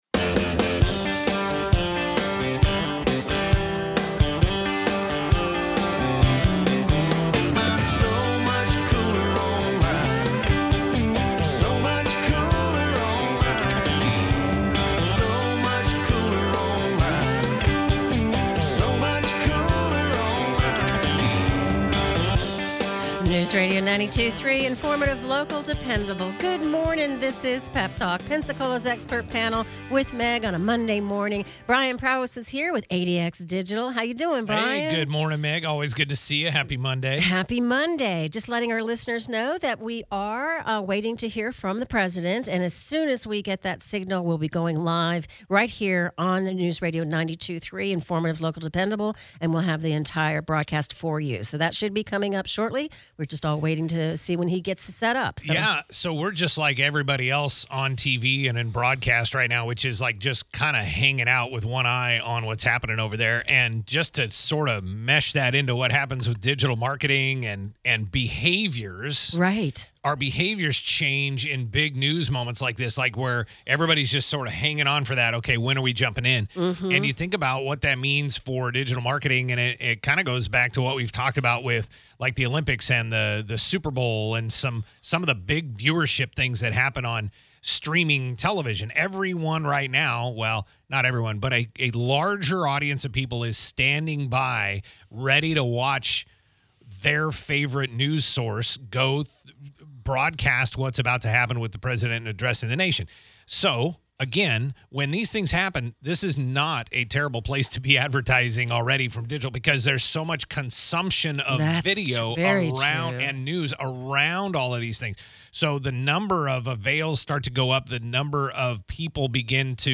(Today's episode was interuppted by a live broadcast of President Trump's press conference.)